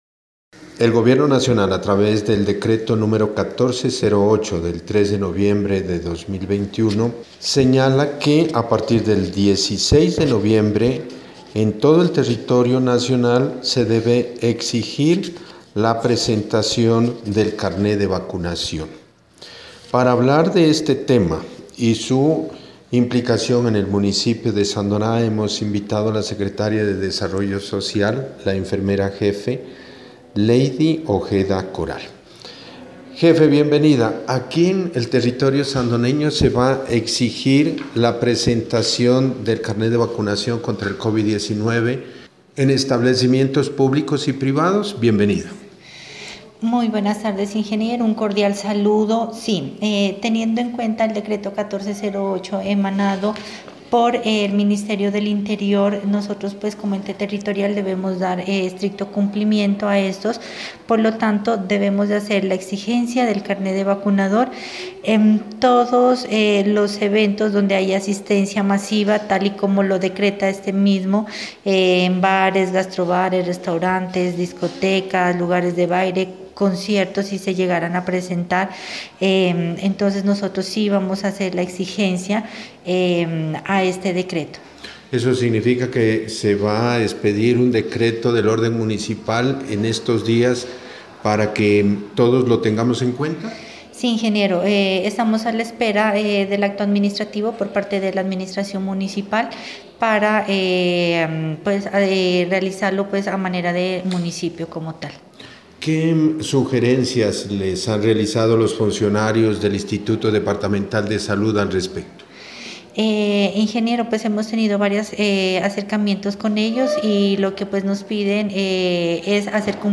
Entrevista con la secretaria de desarrollo social Leydy Ojeda Coral.